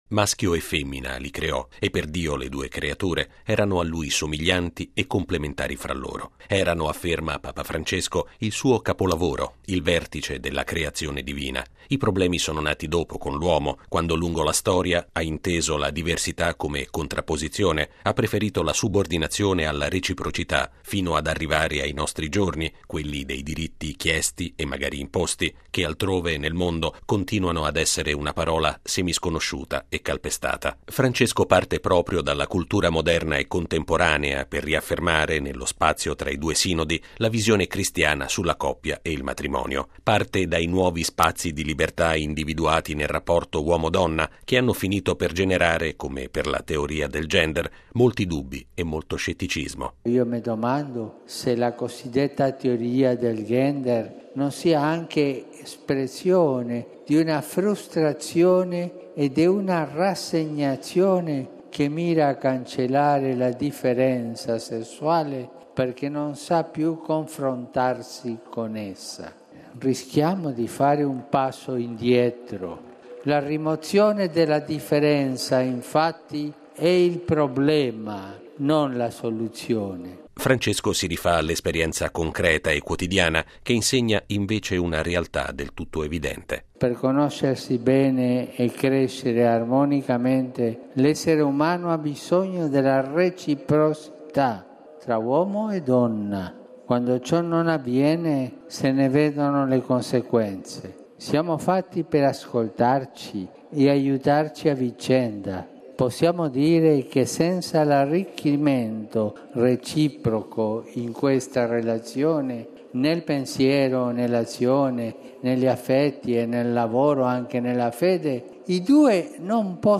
La teoria del “gender” invece di essere una soluzione per i problemi di rapporto tra uomo e donna rischia di essere “un passo indietro”. Lo ha affermato Papa Francesco all’udienza generale in Piazza San Pietro, davanti a circa 50 mila persone.